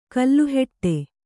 ♪ kalluheṭṭe